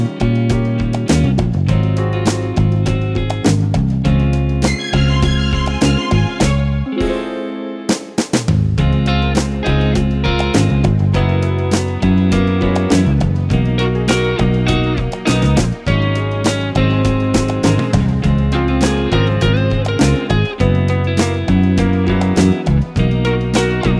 Soul / Motown